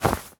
foley_cloth_light_fast_movement_15.wav